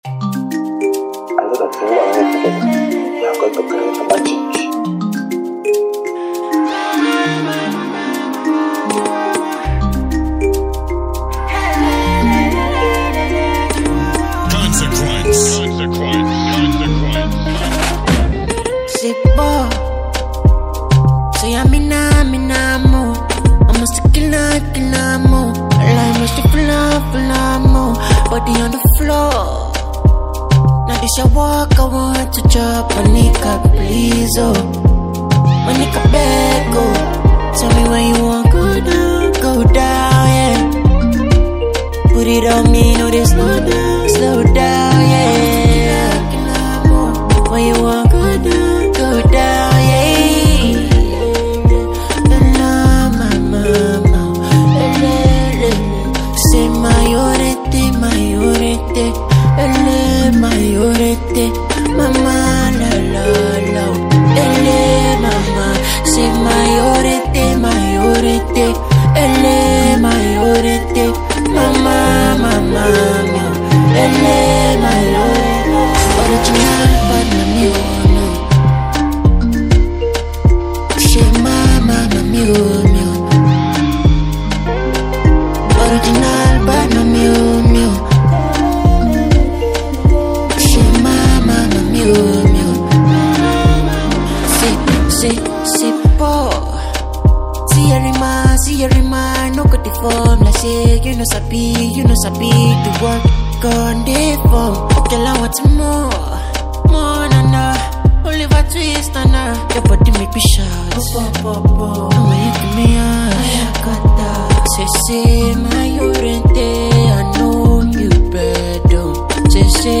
talented pop sensational singer